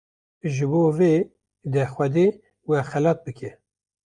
Pronounced as (IPA) /xɛˈlɑːt/